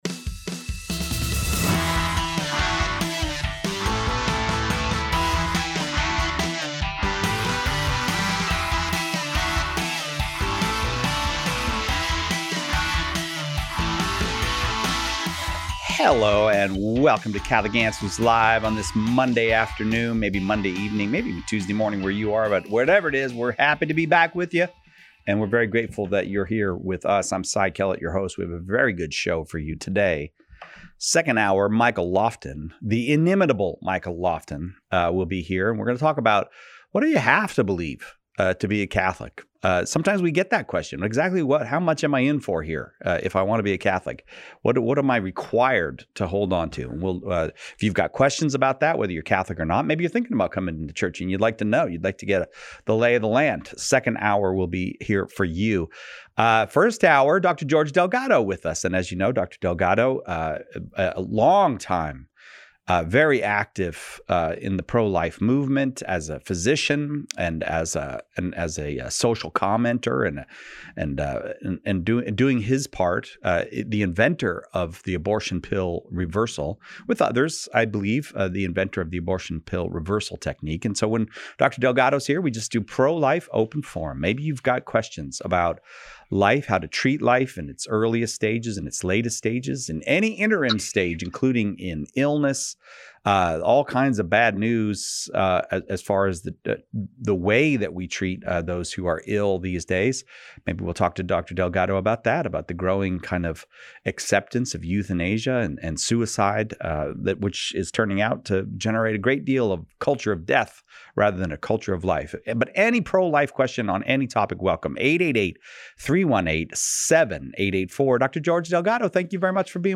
Pro-Life Open Forum